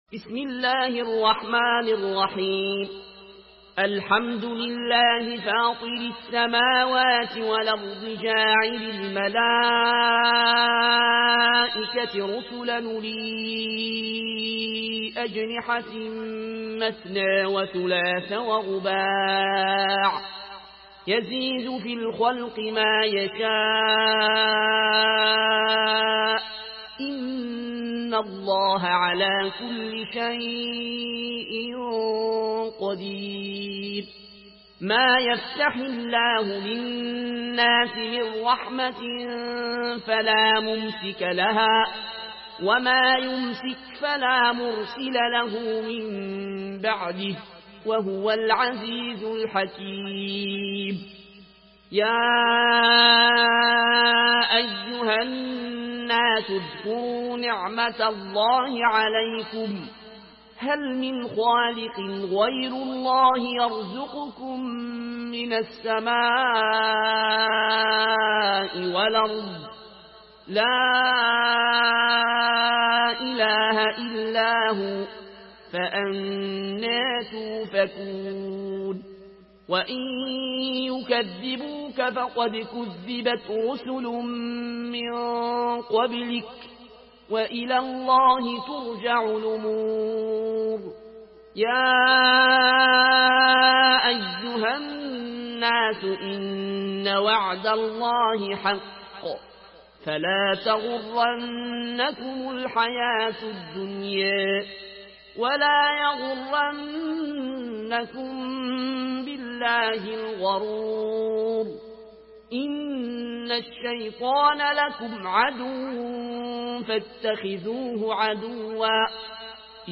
مرتل ورش عن نافع From الأزرق way